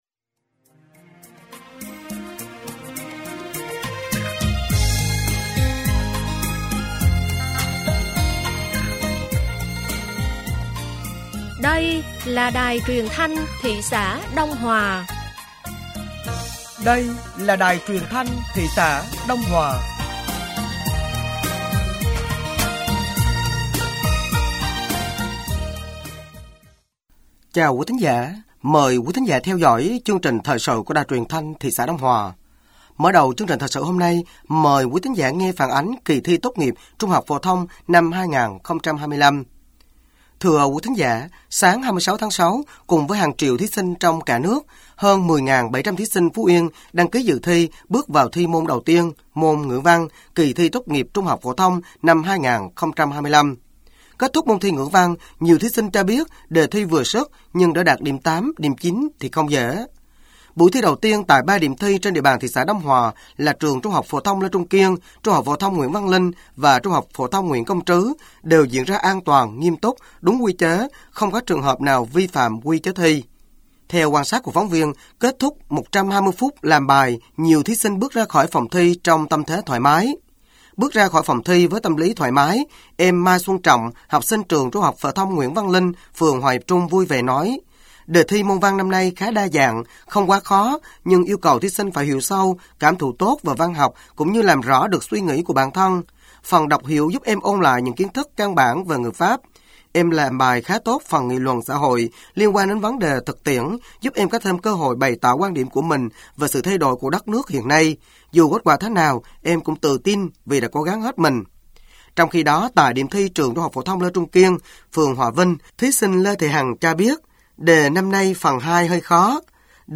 Thời sự tối ngày 26/6/2025 sáng ngày 27/6/2025